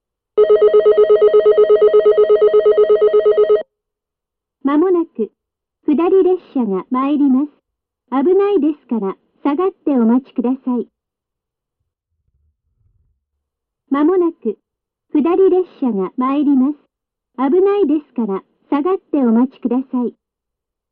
自動放送
下り接近放送
・スピーカー：Panasonicクリアホーン
・接近放送にベルが使われているのは、この辺では山寺駅とここだけかと思います。